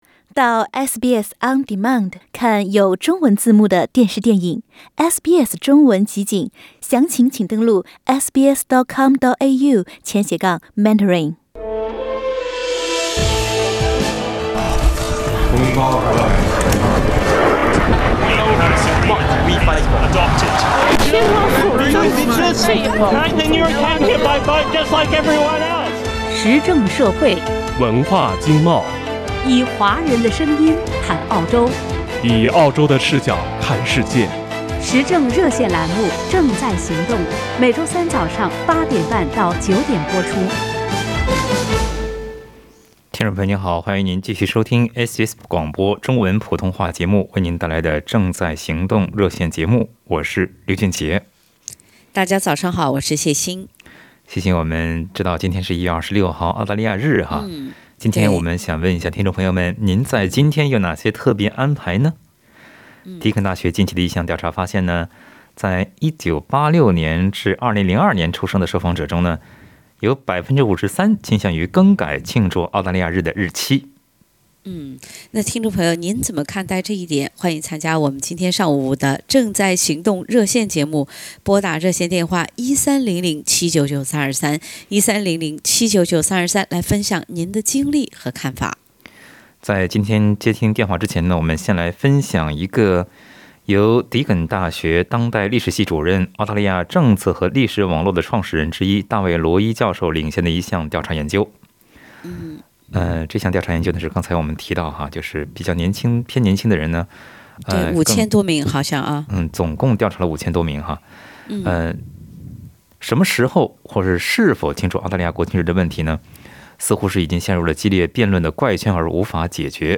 在本期《正在行动》热线节目中，听友们表达了对更改澳大利亚日庆祝日期的看法。